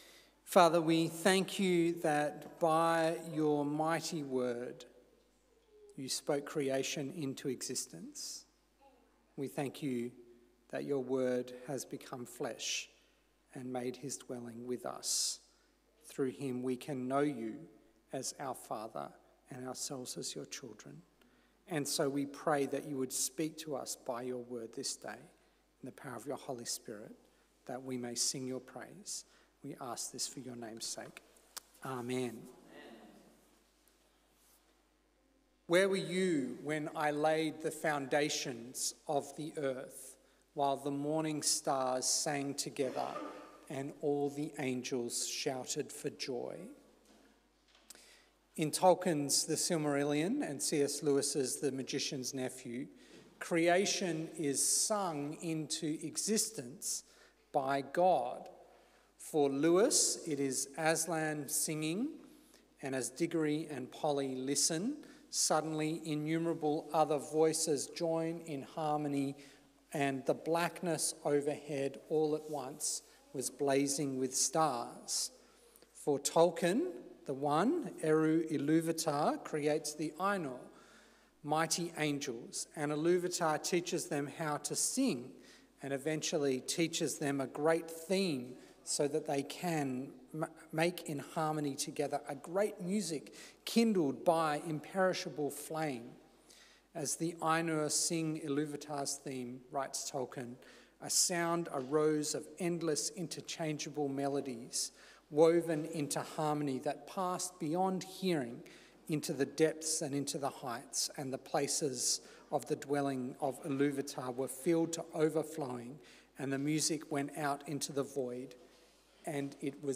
A sermon on Psalm 148